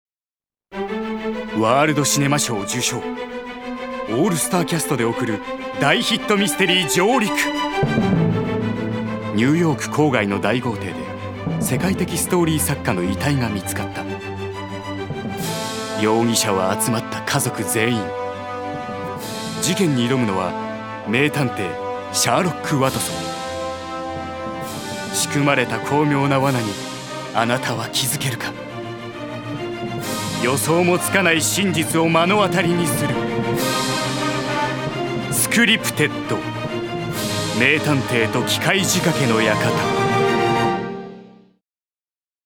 所属：男性タレント
ナレーション２